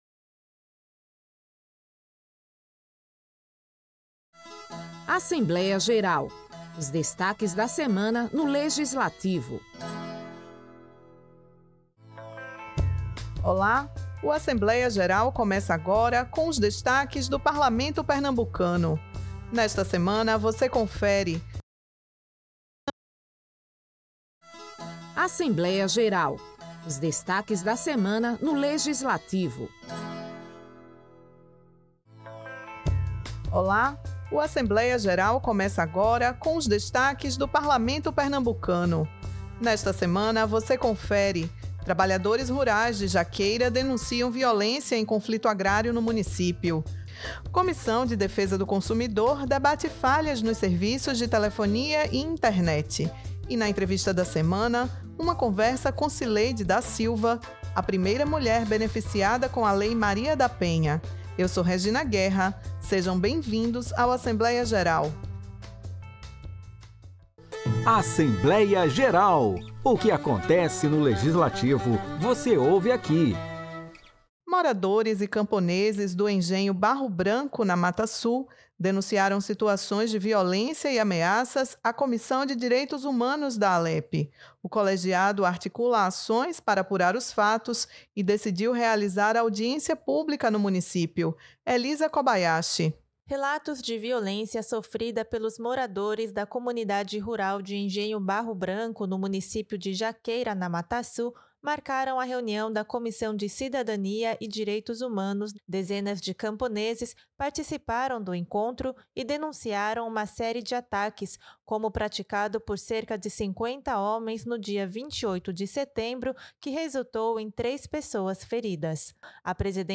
O programa também traz os debates da Comissão de Defesa do Consumidor sobre falhas nos serviços de telefonia e internet, com a participação de representantes das operadoras Claro, Tim e Vivo.